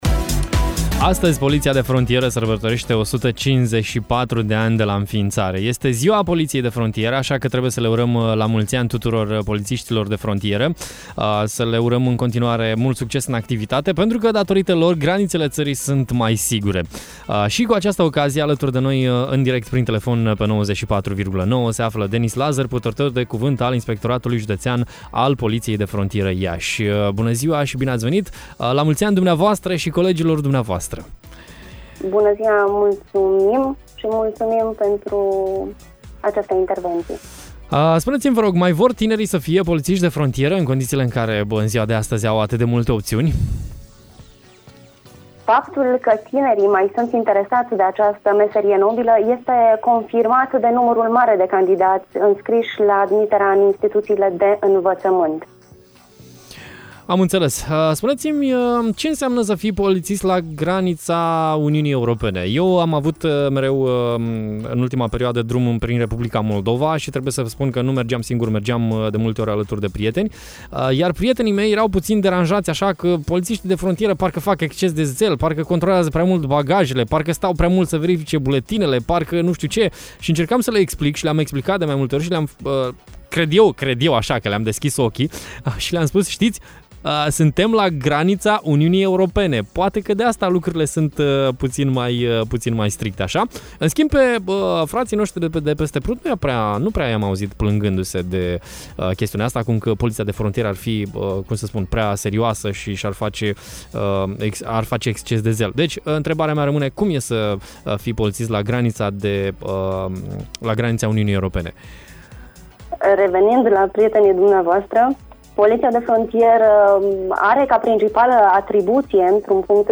live la Radio Hit